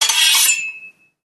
Free Samples : samples de effects .Efectos especiales,sonidos extrańos..